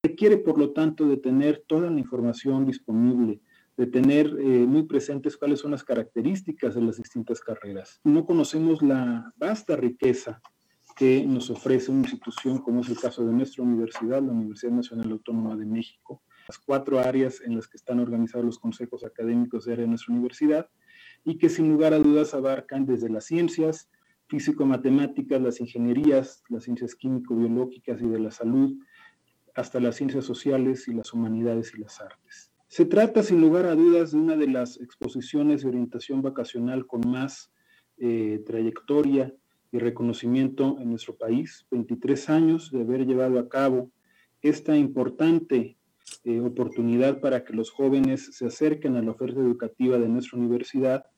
Al clausurar el Webinar “Al Encuentro del Mañana. La oferta educativa de la UNAM en voz de sus directoras y directores”, indicó que la elección de carrera es, sin lugar a duda, una de las más grandes decisiones que puede tomar una persona en su vida.